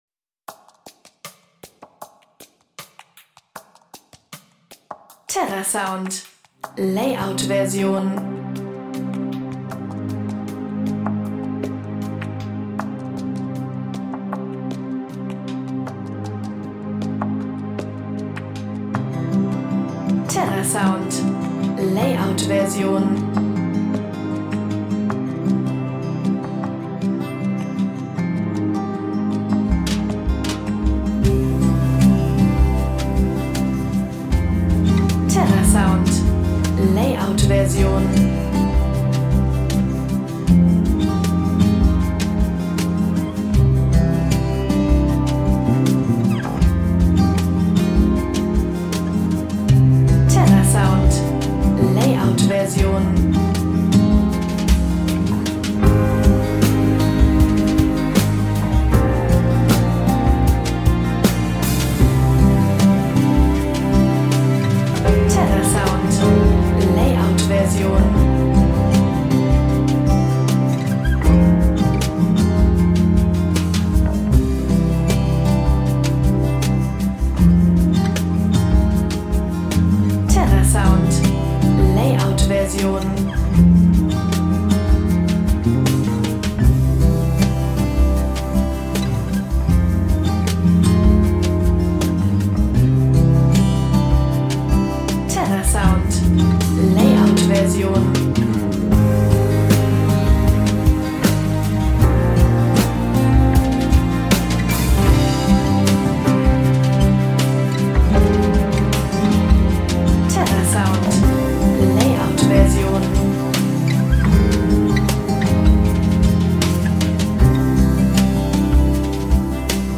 Tempo: 78 bpm